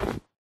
Sound / Minecraft / step / snow1